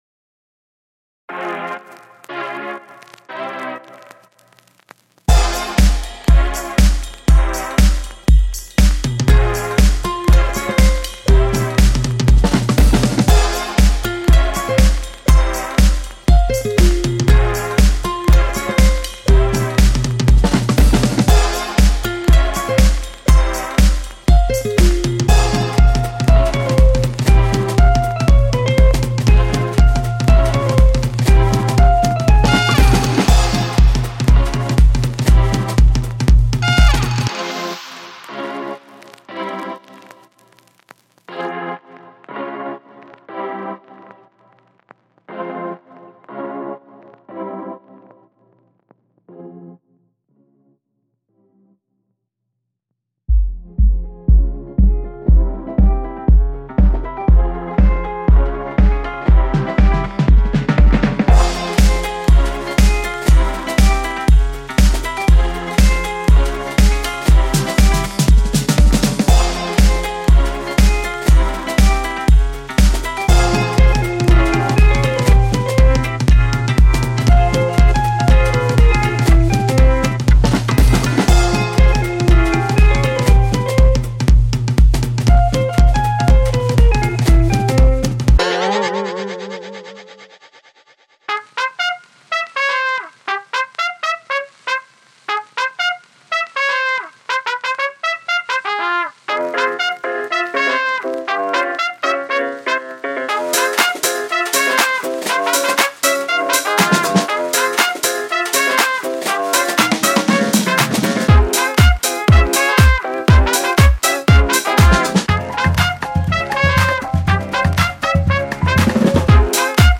آهنگ بی کلام perplexed trumpet